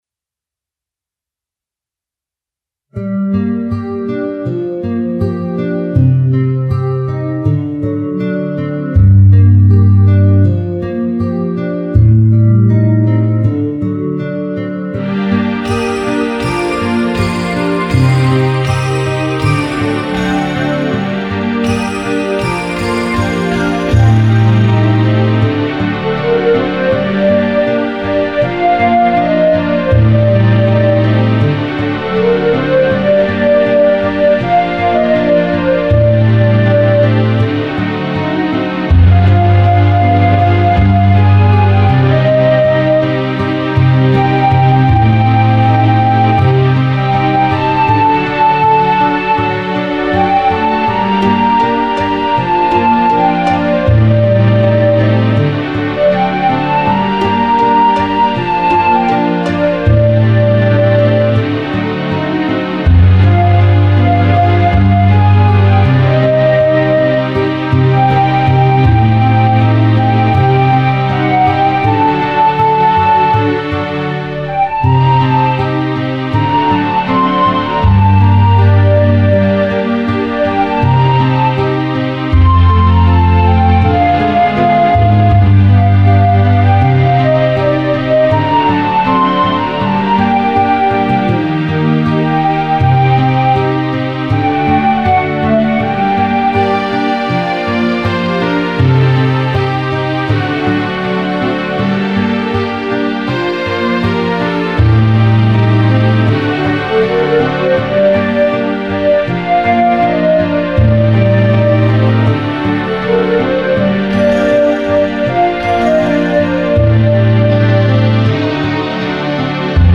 Переведено конвертером Direct MIDI to MP3 Converter